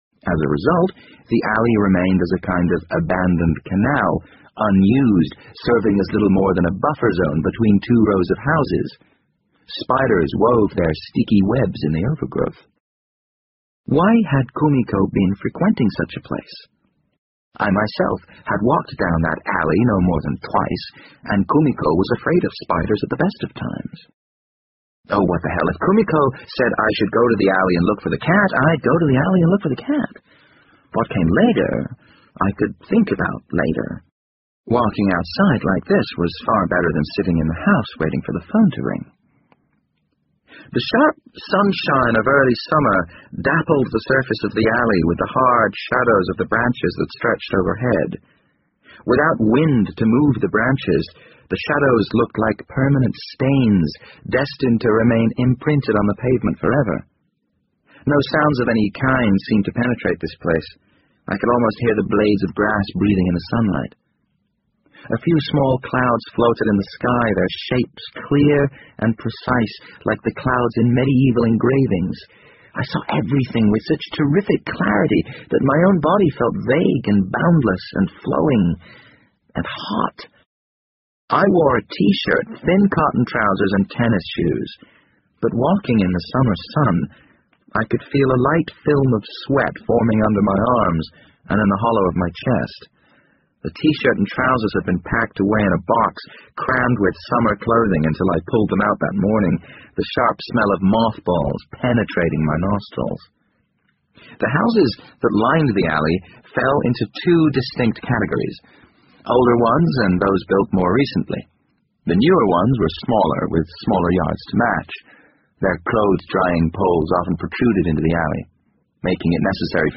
BBC英文广播剧在线听 The Wind Up Bird 5 听力文件下载—在线英语听力室